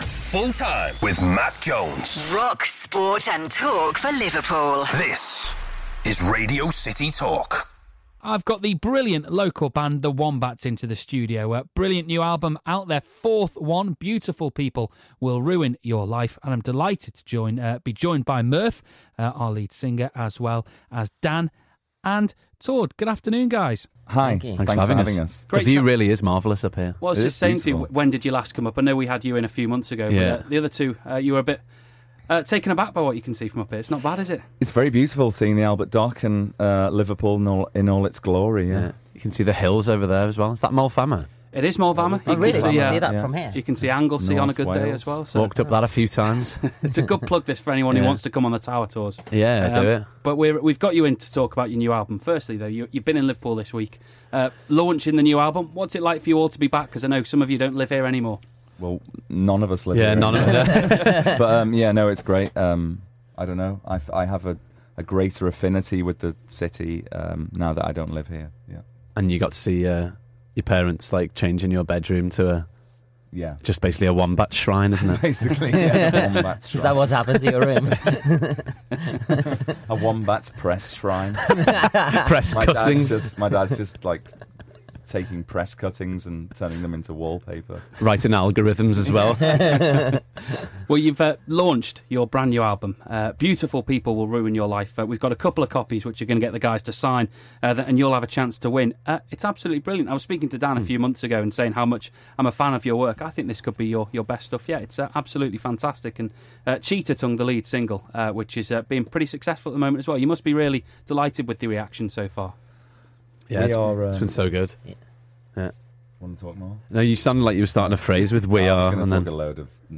for a chat about their brand new album.